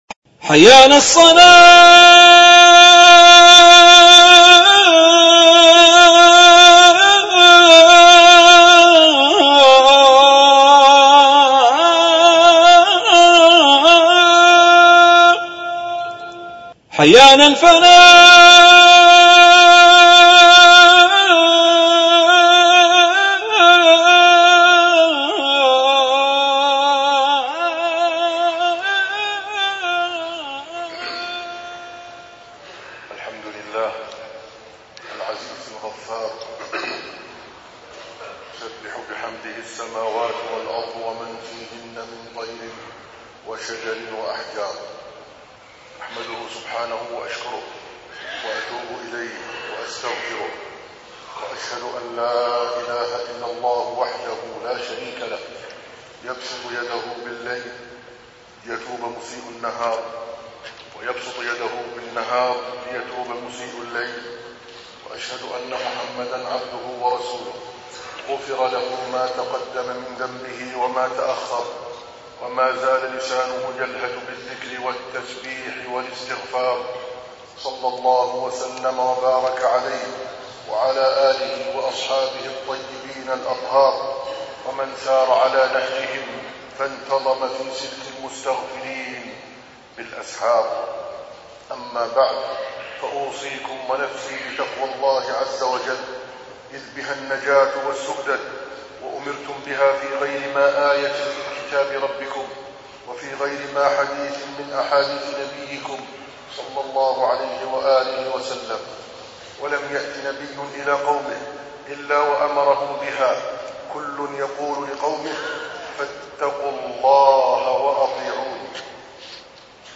التصنيف: خطب الجمعة